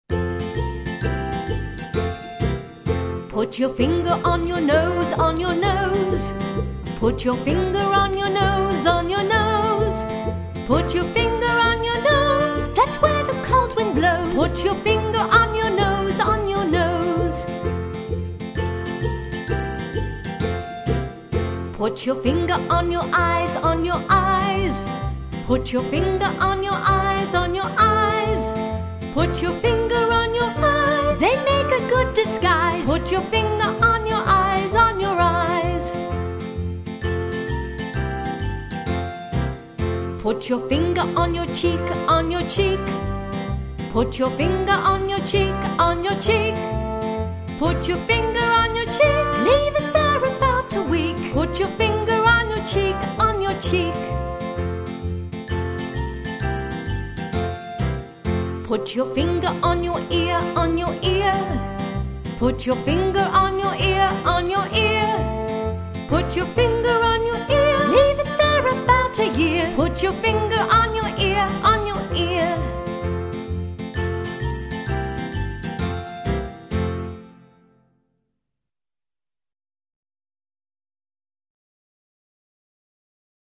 Песни-потешки